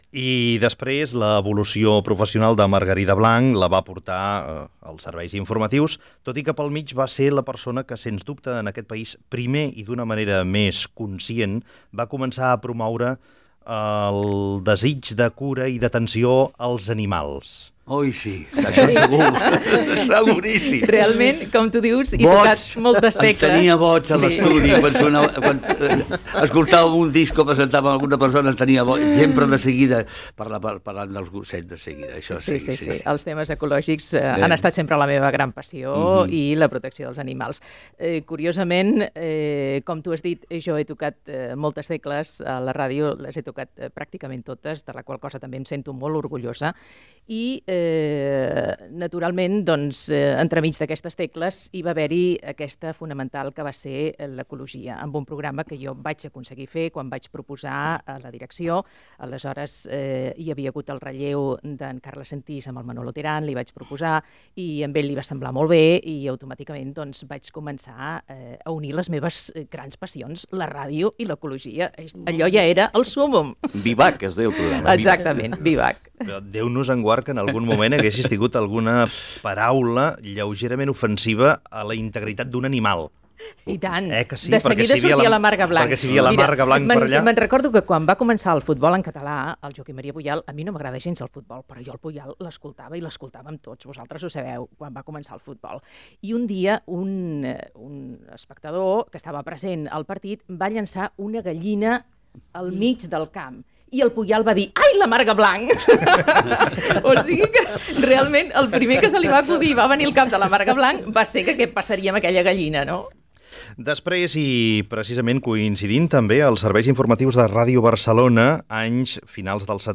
Participació dels oients.
Info-entreteniment
Fragment extret de l'arxiu sonor de COM Ràdio.